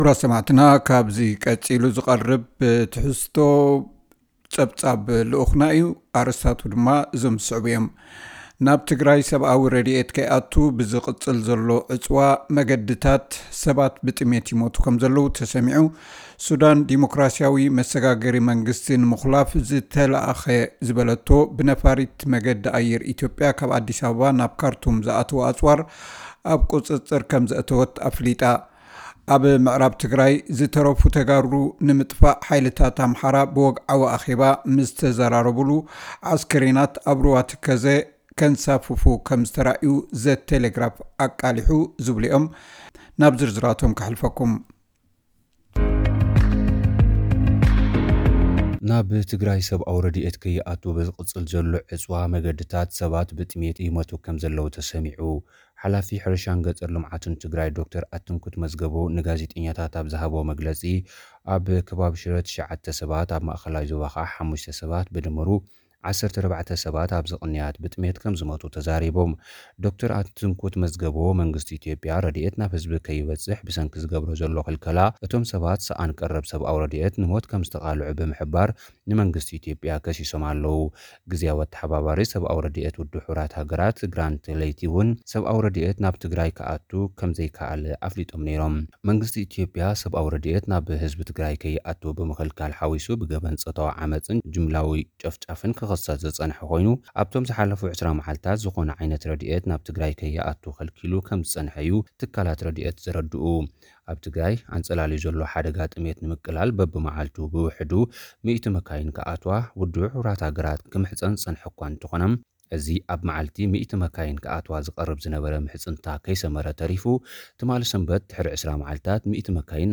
ሓጺር ጸብጻብ፥ ናብ ትግራይ ሰብኣዊ ረድኤት ከይኣቱ ብዝቕጽል ዘሎ ዕጽዋ መገድታት ሰባት ብጥሜት ይሞቱ ከም ዘለዉ ተሰሚዑ። ሱዳን ዴሞክራሲያዊ መሰጋገሪ መንግስቲ ንምኹላፍ ዝተልኣኸ ዝበለቶ ብነፋሪት መገዲ ኣየር ኢትዮጵያ ካብ ኣዲስ ኣበባ ናብ ካርቱም ዝኣተወ ኣጽዋር ኣብ ቁጽጽር ከም ዘእተወት ኣፍሊጣ። ኣብ ምዕራብ ትግራይ ዝተረፉ ተጋሩ ንምጥፋእ ሓይልታት ኣምሓራ ብወግዓዊ ኣኼባ ምስተዘራረቡሉ ኣስከሬናት ኣብ ሩባ ተከዘ ከንሳፍፉ ከም ዝተርኣዩ ዘ ቴሌግራፍ ኣቃሊሑ።